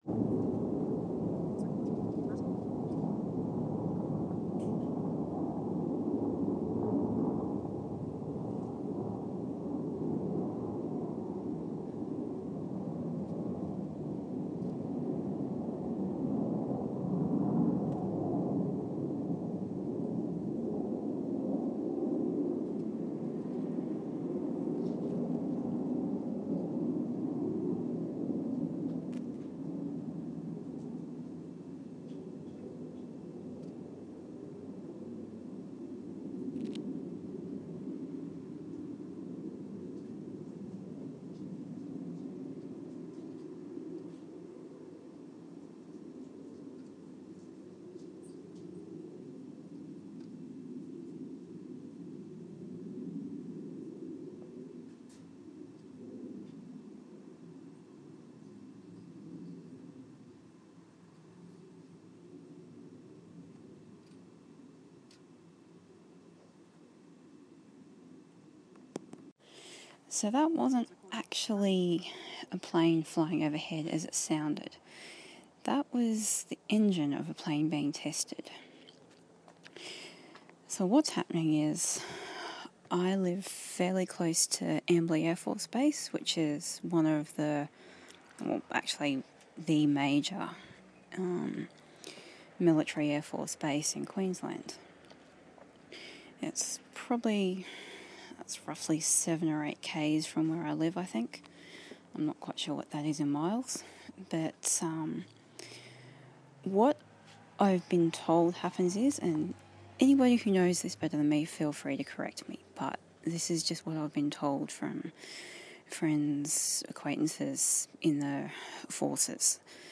fighter-jet engine test
a short snapshot of a fighter-jet's engine being tested. from a distance of-courss.